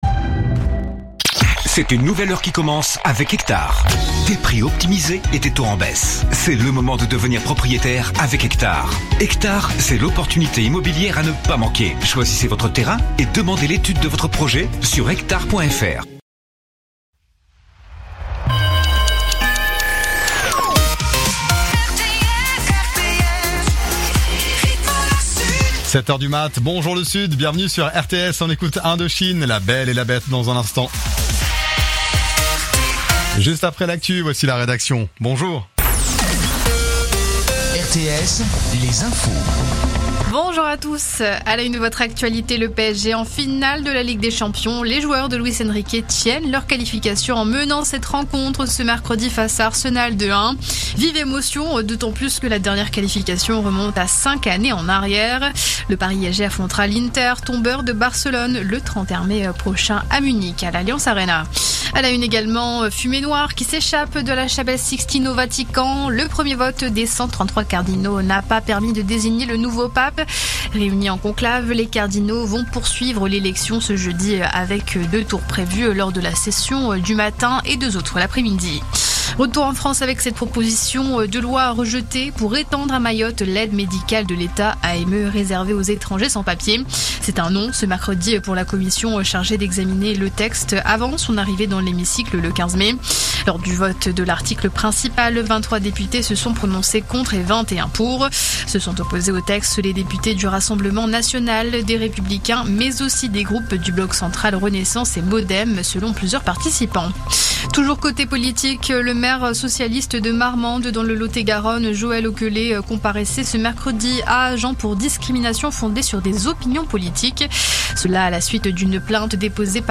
Écoutez les dernières actus de l'Hérault en 3 min : faits divers, économie, politique, sport, météo. 7h,7h30,8h,8h30,9h,17h,18h,19h.